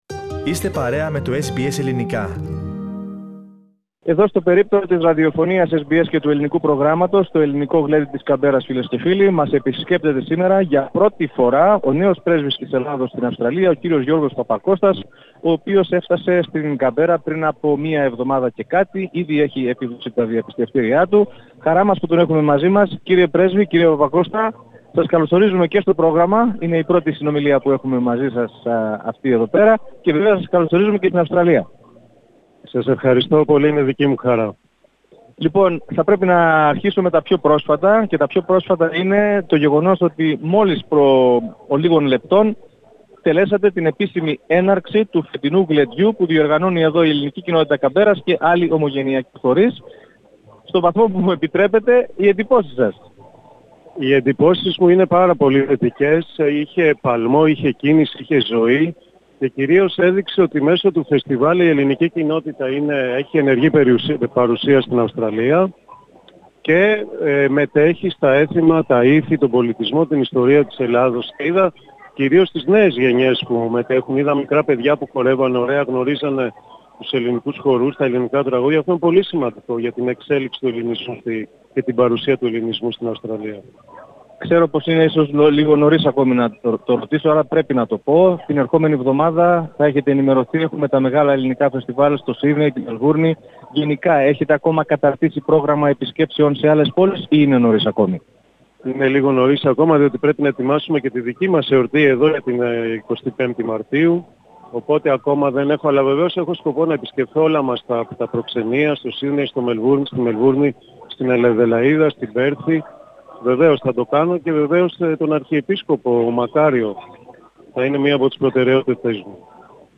Σε συνέντευξή του προς το Ελληνικό Πρόγραμμά της Ραδιοφωνίας SBS , την πρώτη του σε μέσο ενημέρωσης της Αυστραλίας από της αναλήψεως των καθηκόντων του, ο νέος πρέσβης της Ελλάδας στην Αυστραλία, Γιώργος Παπακώστας, αναφέρθηκε με κολακευτικά λόγια στον Ελληνισμό της πρωτεύουσας.